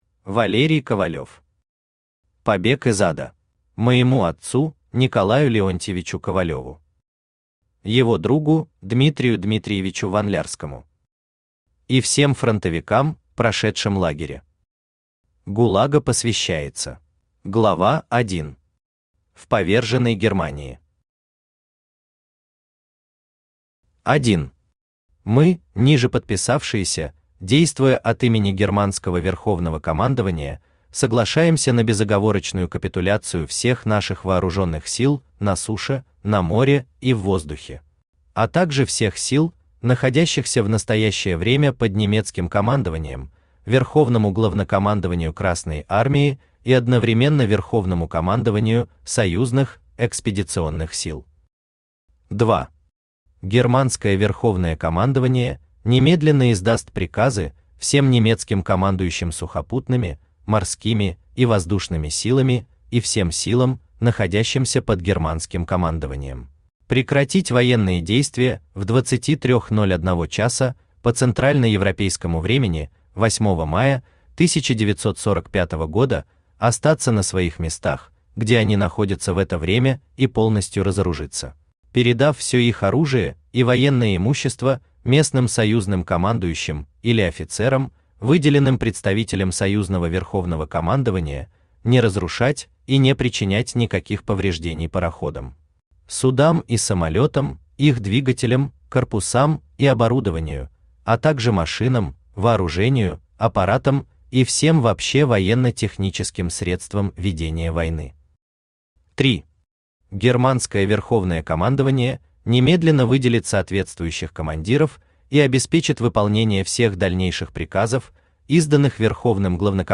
Аудиокнига Побег из ада | Библиотека аудиокниг
Aудиокнига Побег из ада Автор Валерий Николаевич Ковалев Читает аудиокнигу Авточтец ЛитРес.